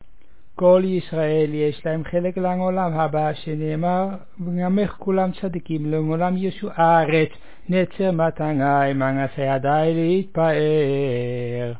The service at the unveiling of a Matseva (tombstone) at Beth Haim in Ouderkerk aan de Amstel.
In contrast to the mitsva (levaya), there is no singing: all texts are recited.
Recited